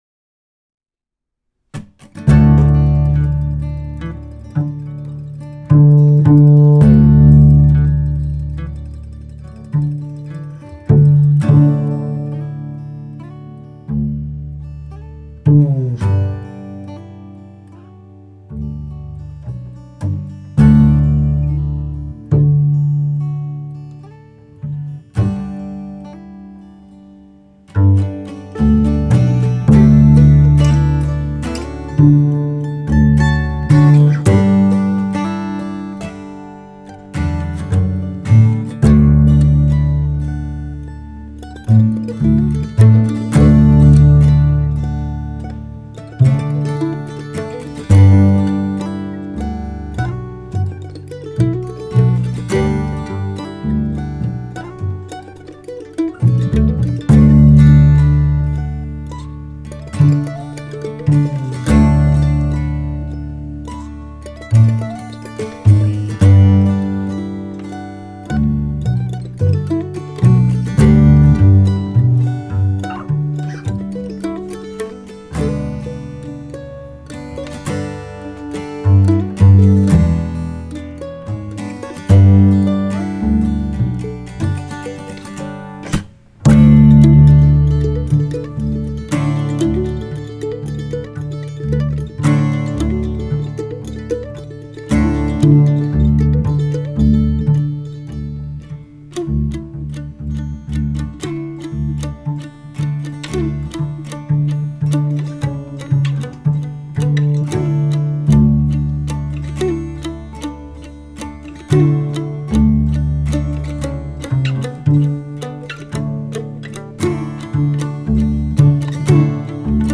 Folk/Acoustic 3:14 Filmscore